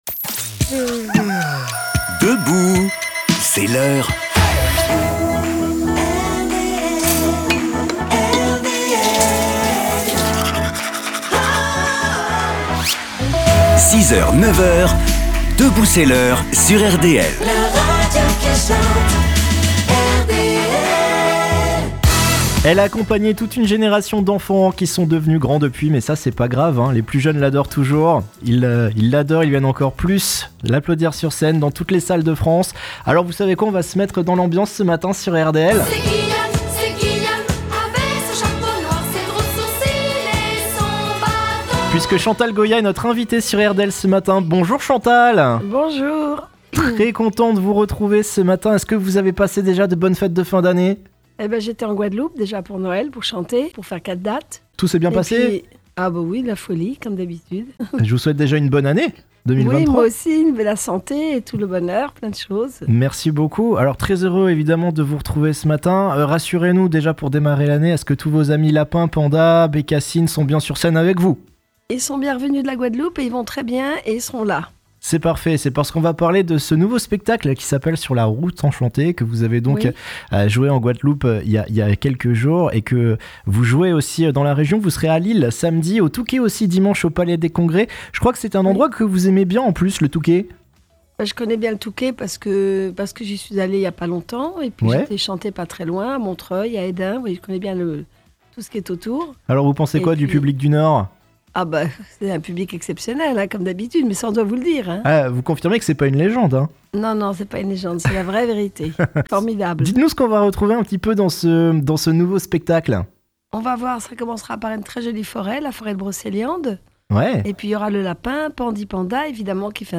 Chantal Goya est l'invitée de Debout C'est l'Heure pour son nouveau spectacle "Sur la route enchantée"!